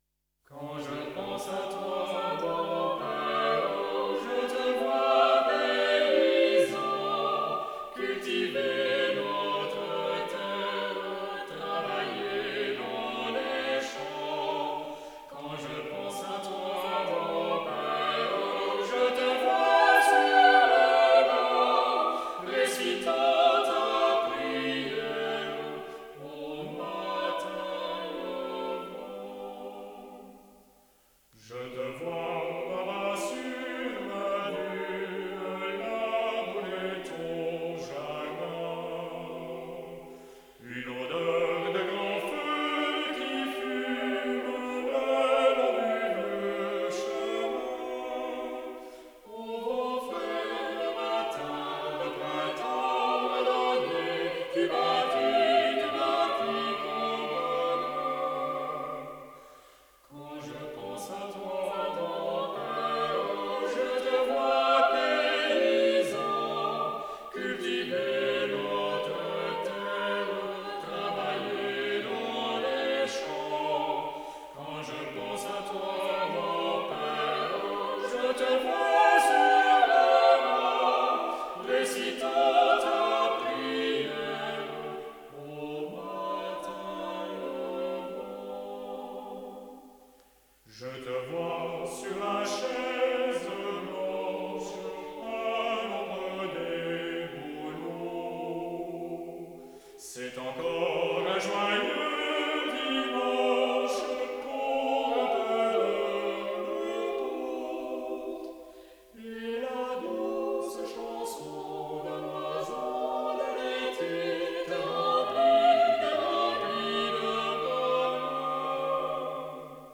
Chœur d’hommes fondé en 1860
Interprété par le Chœur du Léman en : 2012, 2022
H10059-Live.mp3